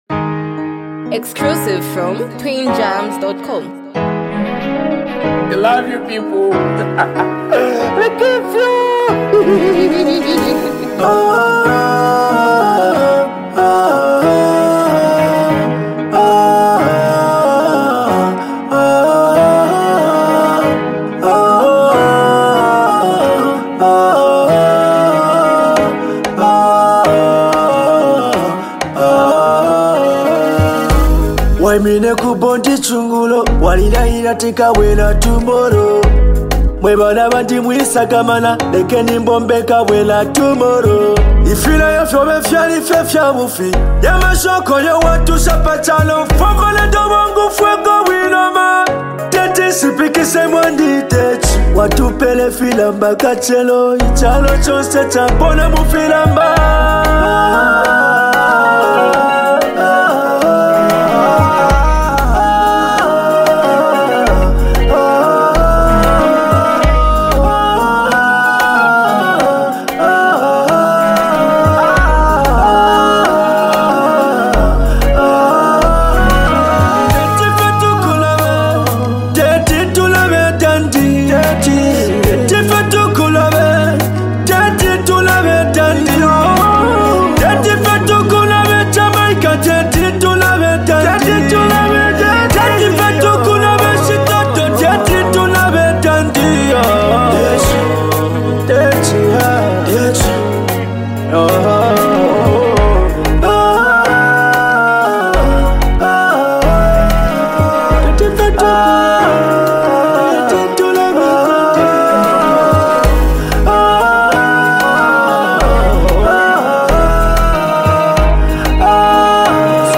Tribute Song